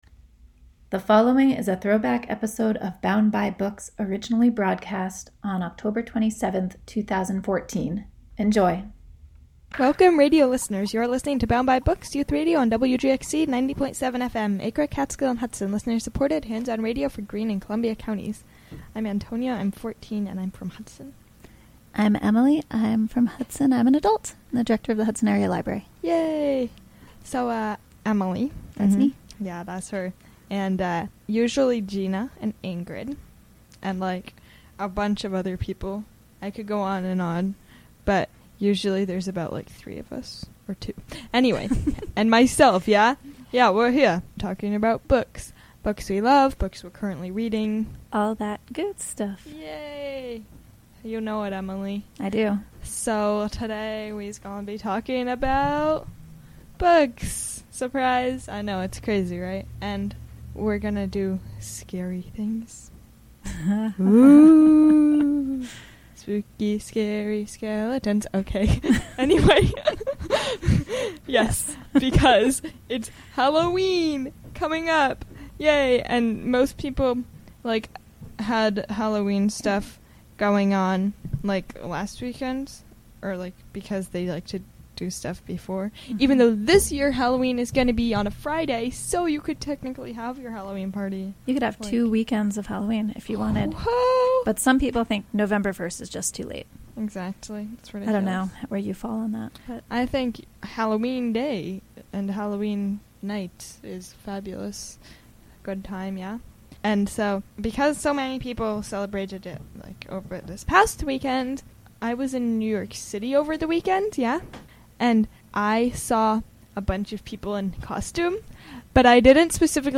"Bound By Books" is hosted by tweens and teens, Fa...